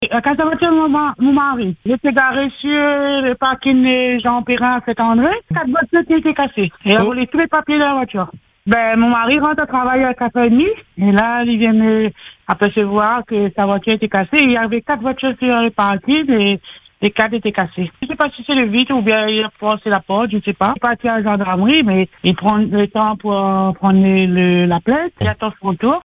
Réécoutez l’intervention de cette dame sur les ondes de Free Dom.